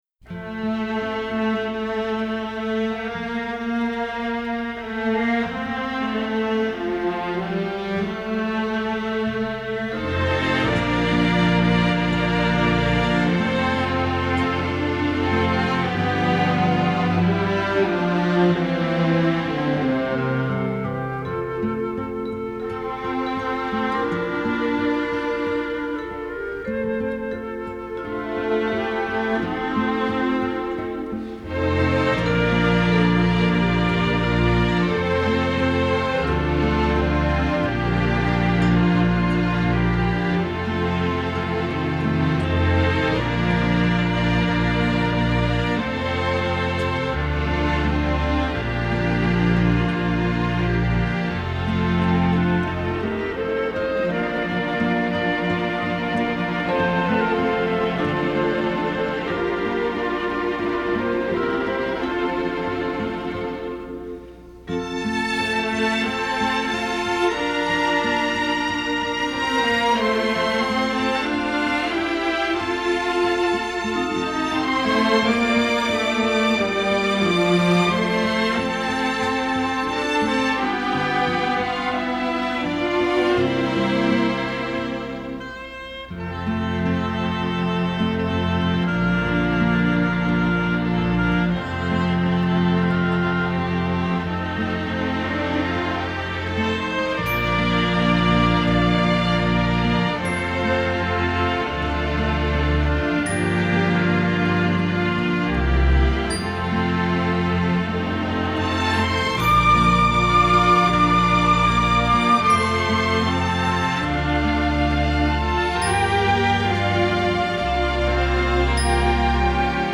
Жанр: Soundtrack, Folk, World, & Country, Stage & Screen